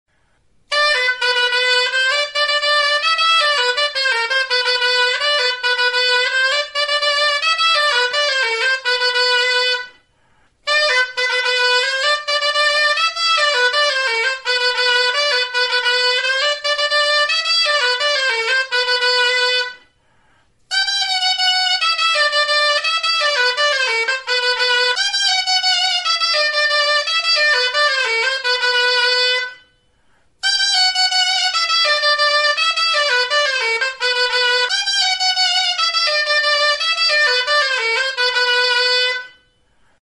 Music instrumentsDULTZAINA; DULZAINA
Aerophones -> Reeds -> Double (oboe)
Recorded with this music instrument.
Mihi bikoitzeko soinu-tresna da.